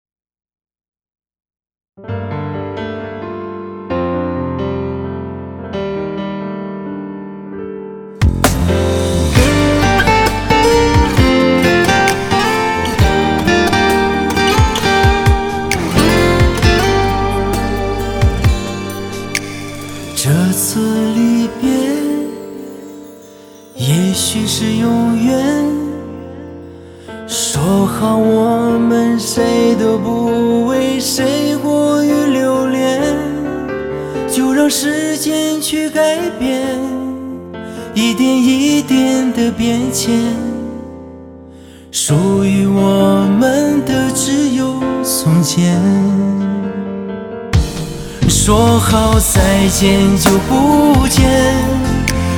这里有最好的情歌，最当红的歌手，最流行的元素，精选珍品情歌，尽显流行风尚。